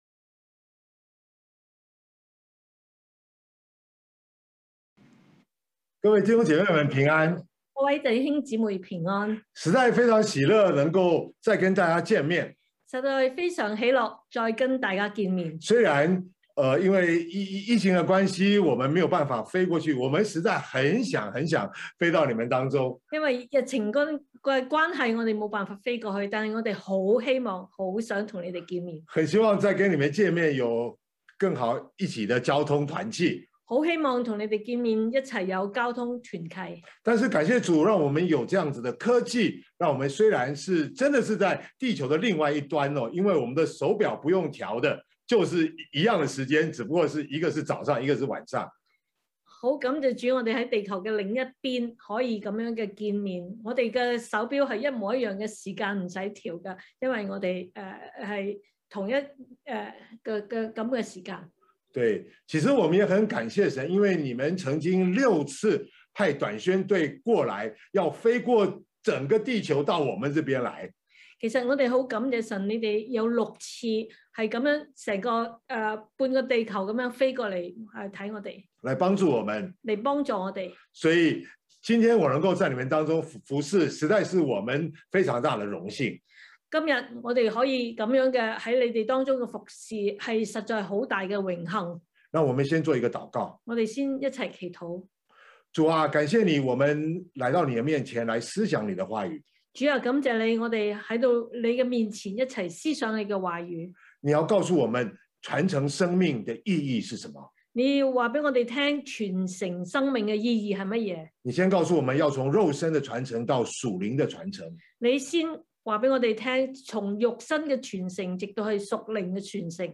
9/12/2021 講道經文：《馬太福音》Matthew 28:18-20 本週箴言：《馬太福音》Matthew 28:18-20 耶穌說：「天上地下所有的權柄都賜給我了。